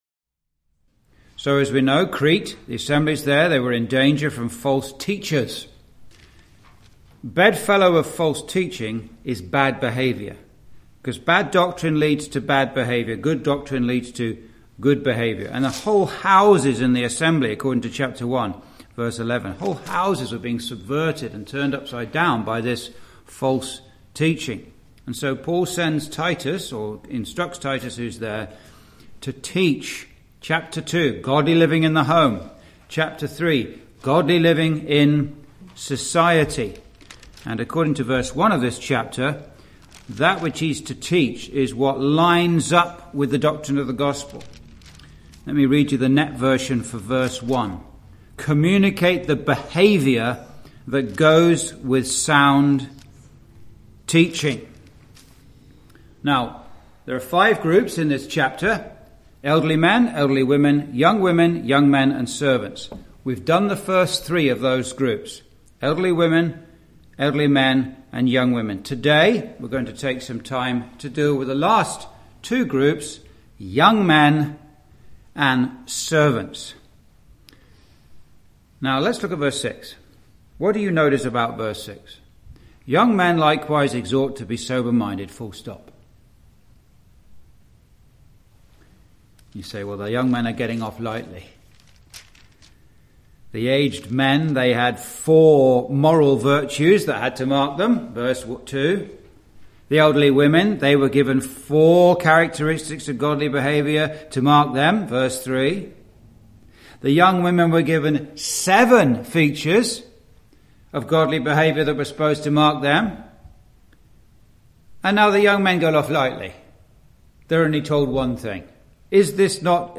(Message preached in Chalfont St Peter Gospel Hall, 2022)
Verse by Verse Exposition